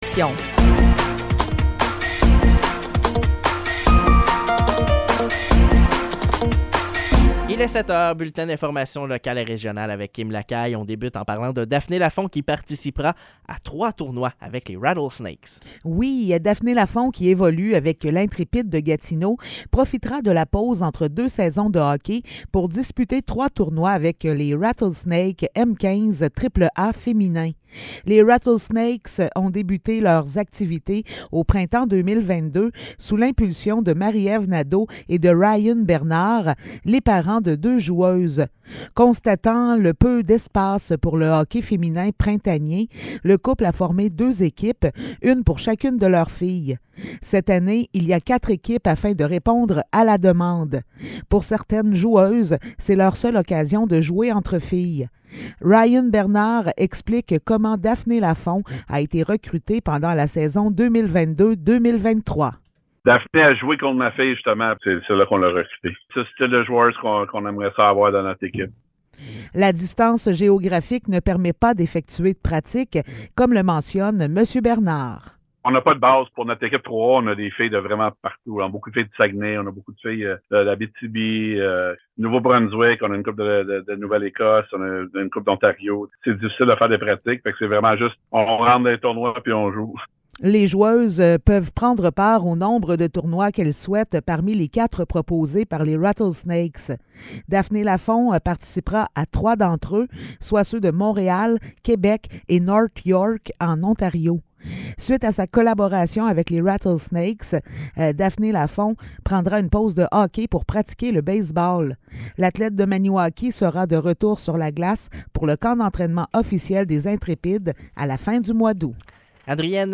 Nouvelles locales - 20 avril 2023 - 7 h